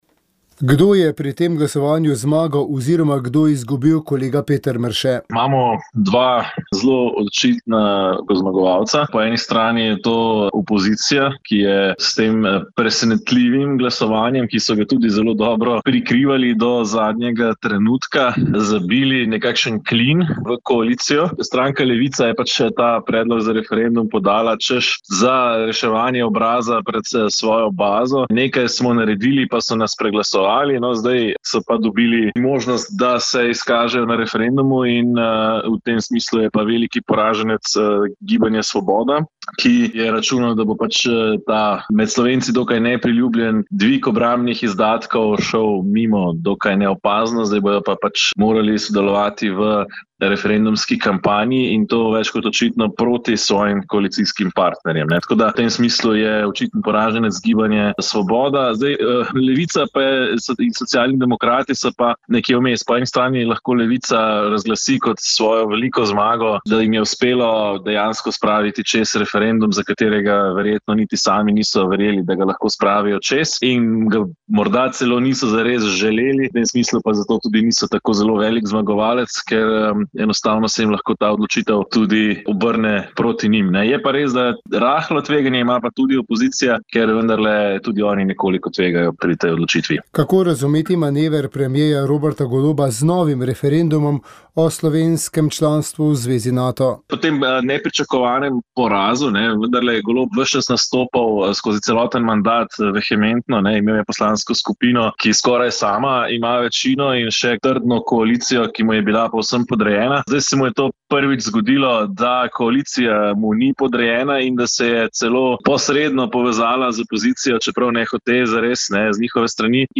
V kapeli Božjega usmiljenja Pod Krenom v Kočevskem Rogu je bila tradicionalna slovesnost za pobite domobrance in druge žrtve revolucionarnega nasilja. Sveto mašo je daroval upokojeni nadškof Anton Stres. V pridigi je opozoril, da sprava v slovenski družbi še vedno ni bila dosežena. Kot pogoj, da do nje pride, je izpostavil razkritje resnice o pobojih, obsodbo tistih, ki so jih zagrešili, na drugi strani pa odpuščanje.